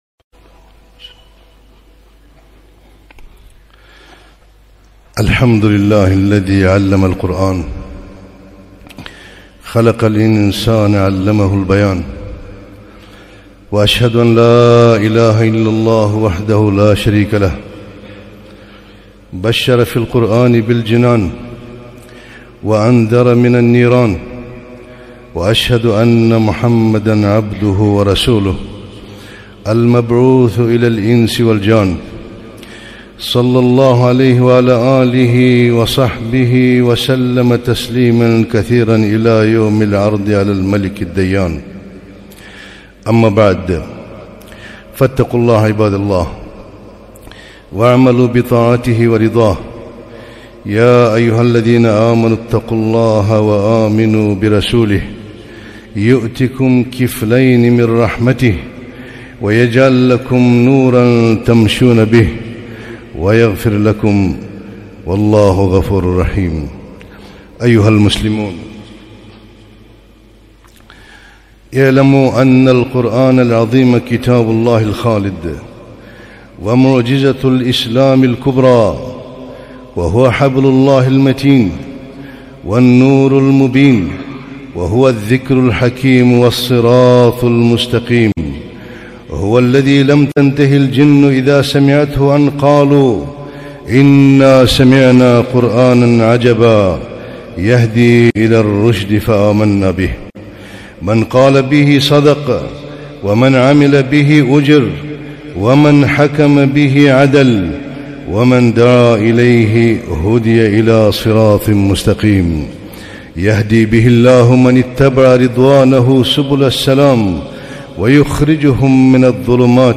خطبة - القرآن فضائل وأحكام